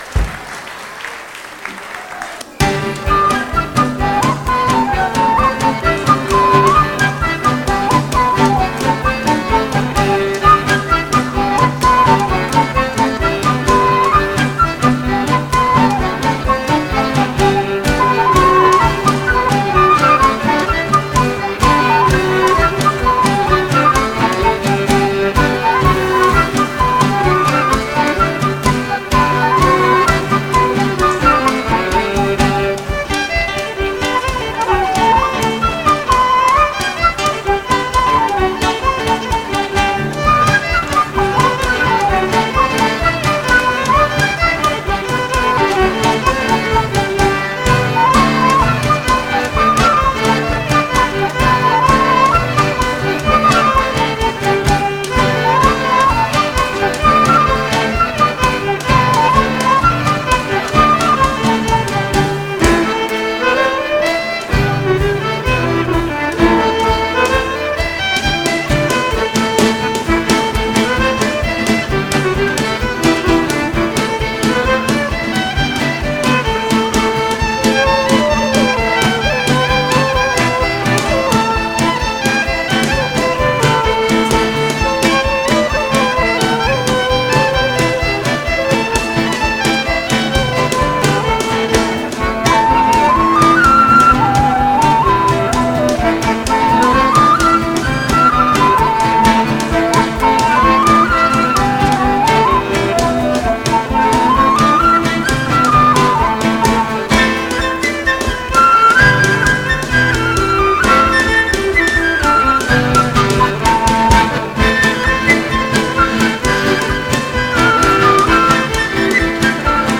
Castleisland polka, Balleydesmond, John Ryan polka (par Draft Company) (Polkas) - Musique irlandaise et écossaise
Suite de polkas jouée par mon groupe Draft et enregistrée en direct en bal.